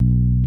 Bass_02.wav